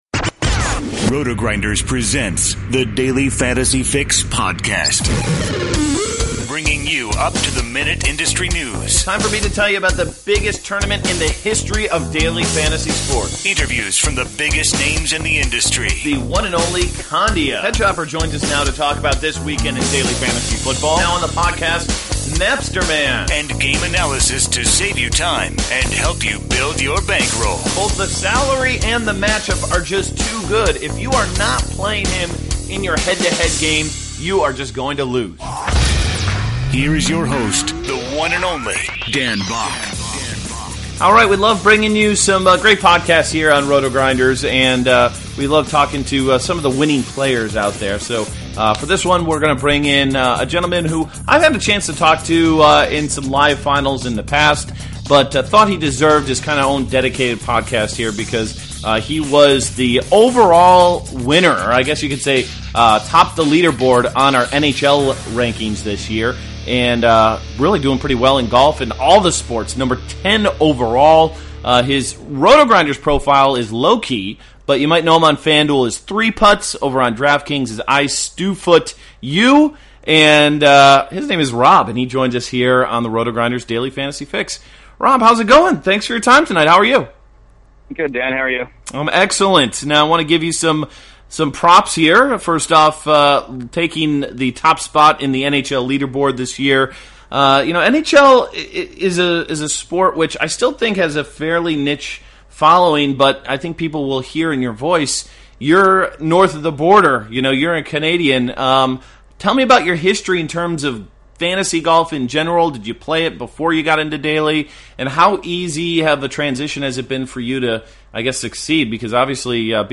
Daily Fantasy Fix: Interview w